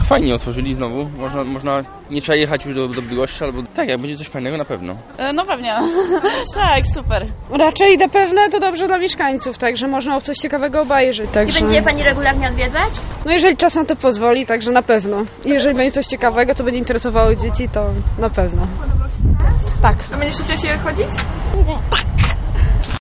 O wrażenia i o to czy mieszkańcy Żnina cieszą się z ponownego otwarcia zapytały nasze reporterki.
1_kino_mieszkancy.mp3